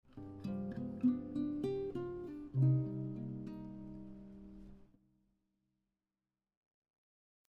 Harmonic minor scale lick 1
The following harmonic minor scale licks outline the chord tones for the 5 to 1 progression in C minor.